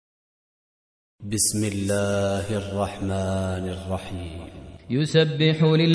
Audio Quran Tarteel Recitation
Surah Repeating تكرار السورة Download Surah حمّل السورة Reciting Murattalah Audio for 64. Surah At-Tagh�bun سورة التغابن N.B *Surah Includes Al-Basmalah Reciters Sequents تتابع التلاوات Reciters Repeats تكرار التلاوات